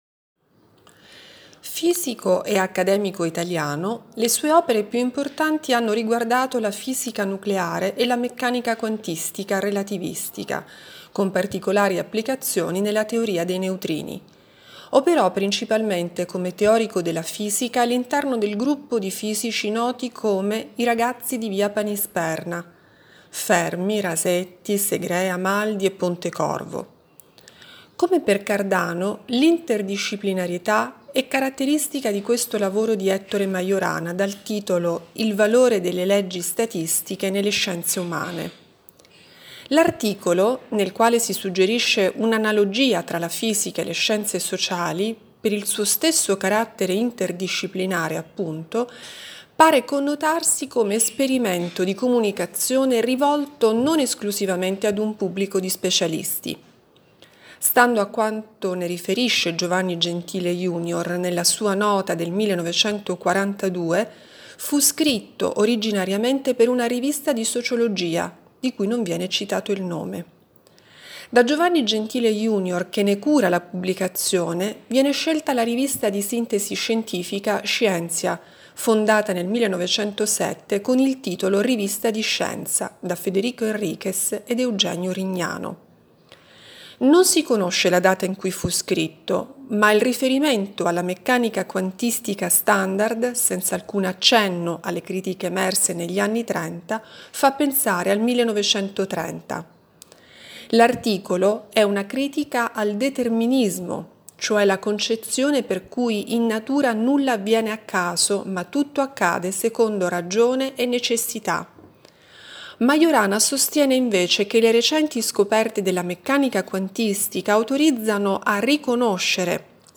Audioguida Volumi Esposti nel 2022